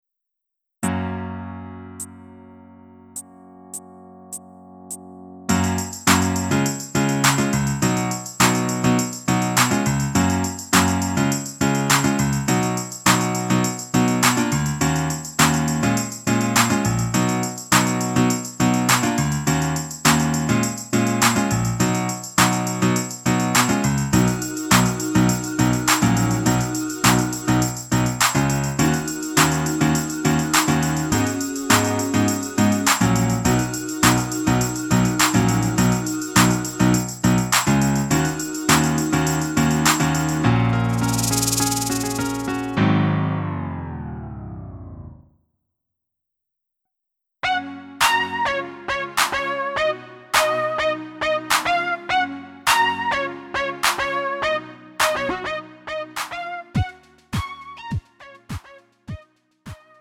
음정 -1키 3:14
장르 구분 Lite MR